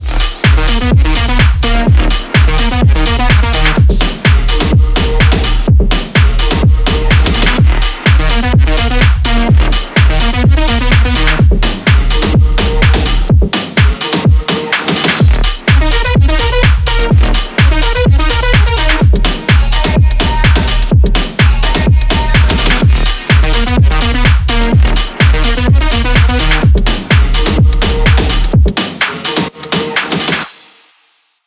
Электроника
клубные # громкие